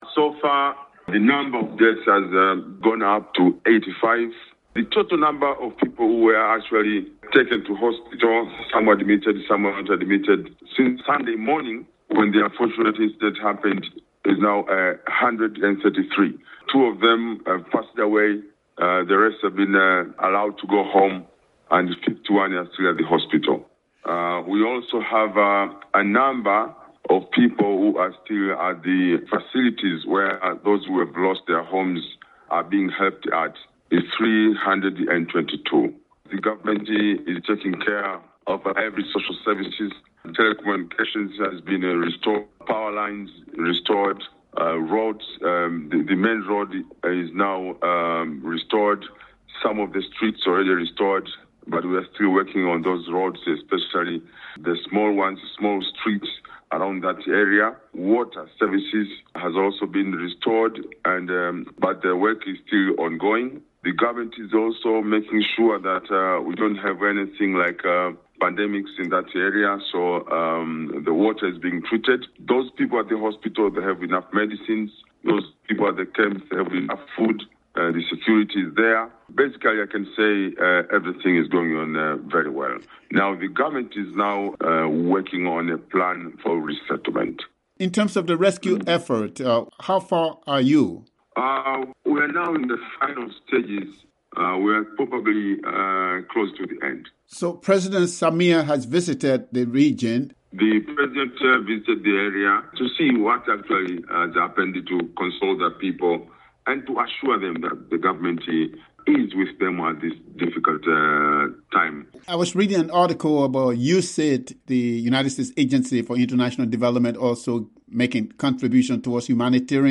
Tanzanian authorities say the death toll from the landslide caused by heavy rainfall on December 3 in the town of Katesh, about 478 miles from the capital, Dar es Salaam rose to 85 over the weekend. Government spokesperson Mobhare (Moh-bah-Rey) Matinyi (Mah-tee-Nyee) says 139 were wounded.